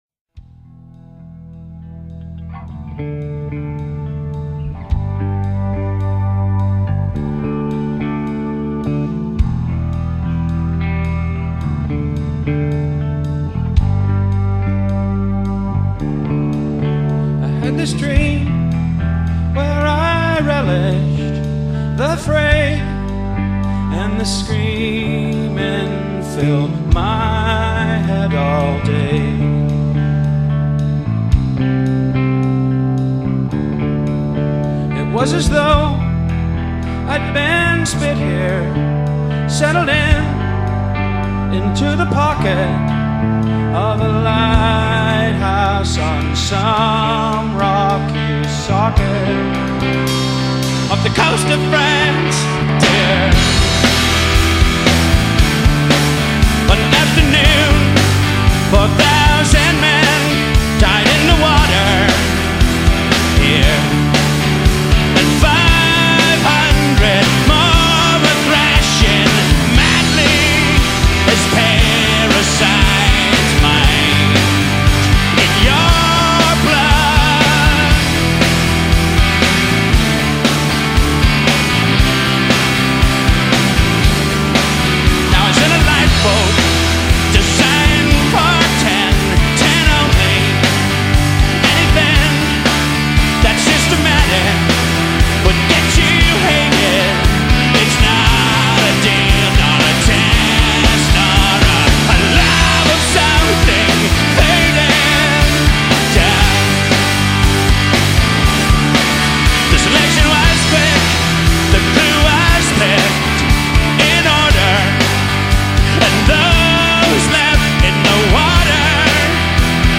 bass
drums
guitar